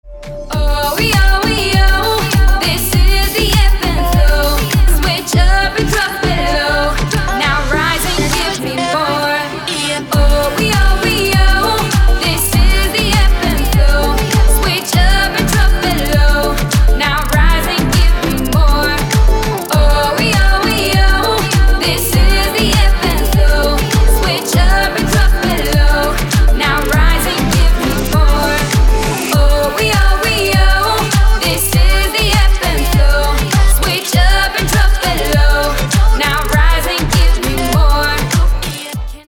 • Качество: 320, Stereo
громкие
женский вокал
deep house
dance
EDM
club